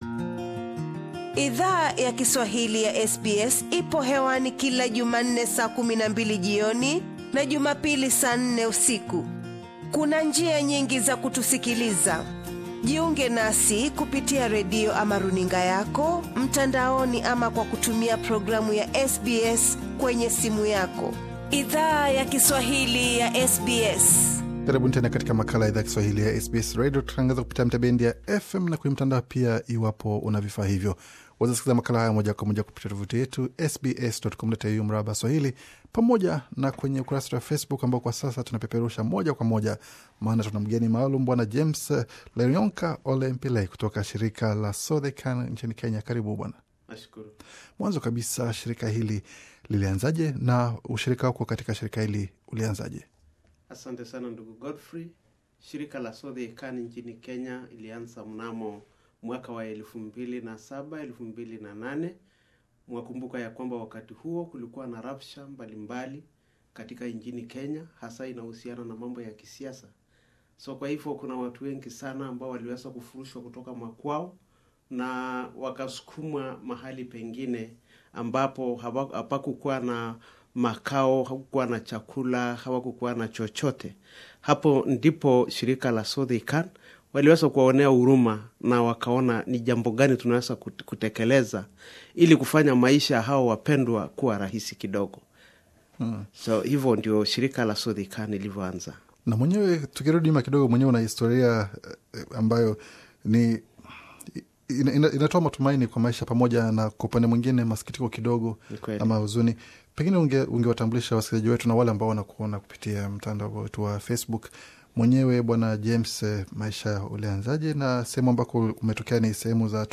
Makao makuu ya shirika hilo yako nchini Australia. Bonyeza hapo juu usikie mahojiano kamili.